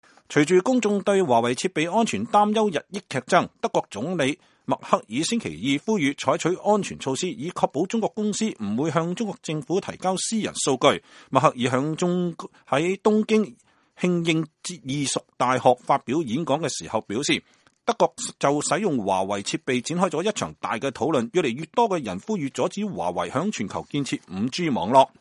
2019年2月5日，德國總理默克爾在東京慶應義塾大學發表演講時表示